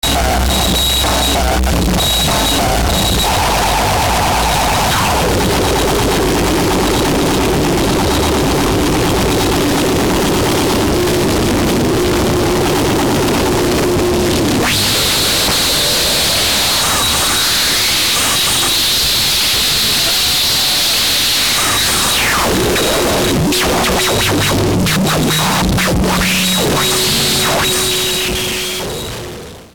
extreme noise
noise, distortion